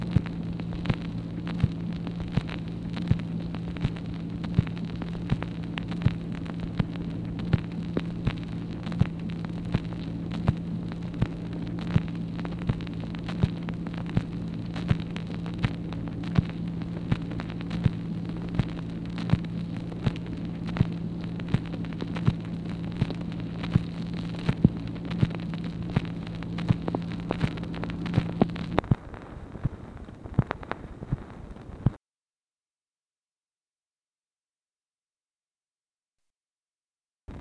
MACHINE NOISE, May 4, 1964
Secret White House Tapes